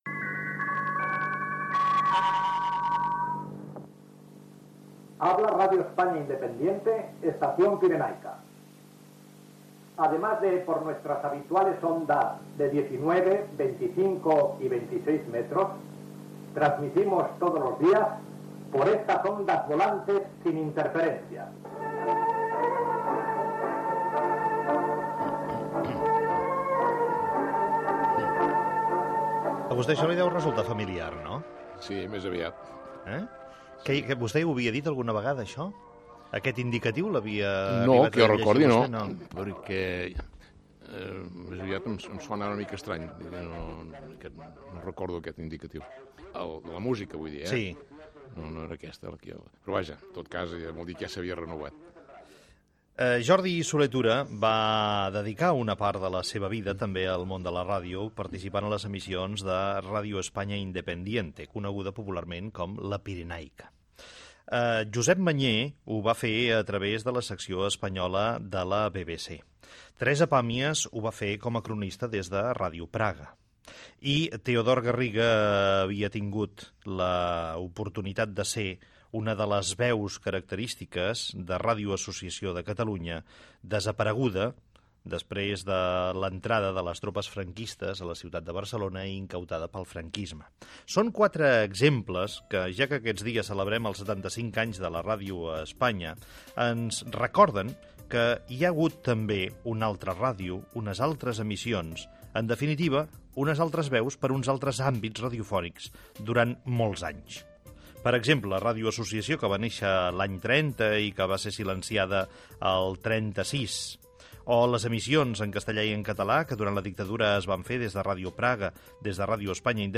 Amb motiu del 75 aniversari de la ràdio, taula rodona amb professionals que van fer ràdio fora d'Espanya durant els anys de la dictadura franquista.
Info-entreteniment